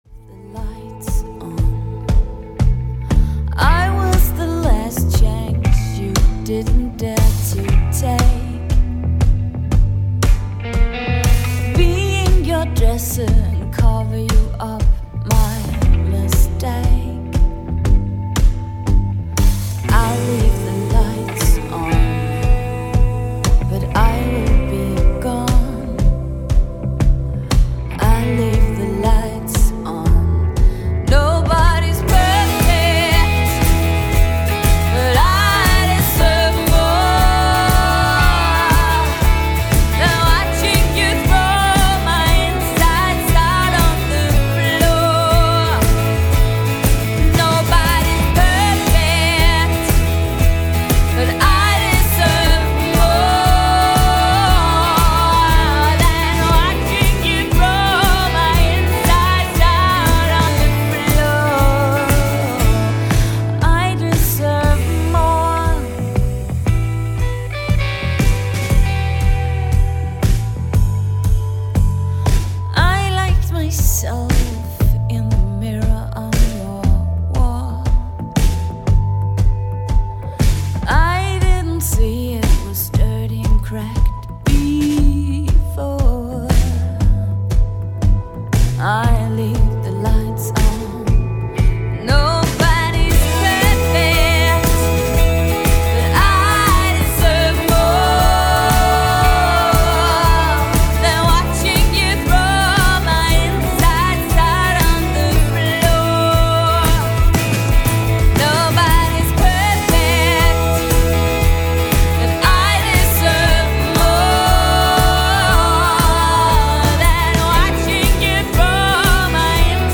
• Pop
• Singer/songwriter
Vokal
Guitar
Trommer